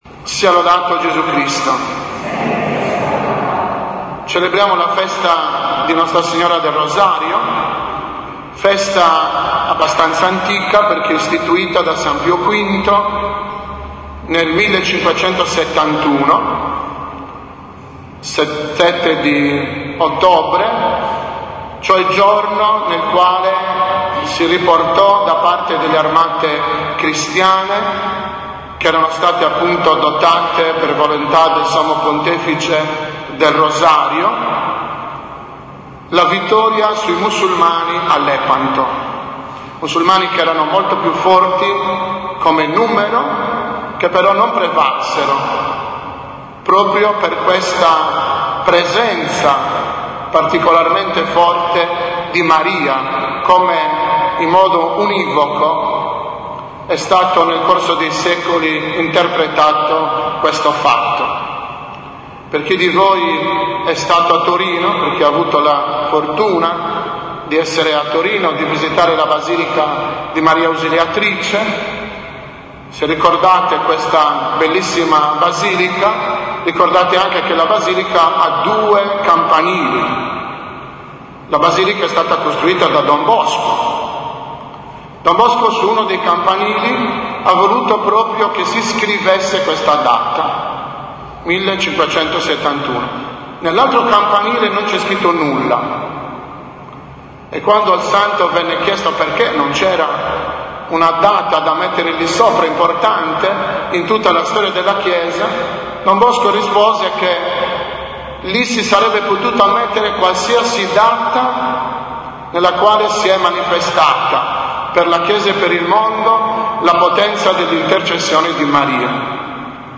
7.10.2014 – OMELIA DELLA MEMORIA DELLA BEATA VERGINE MARIA DEL ROSARIO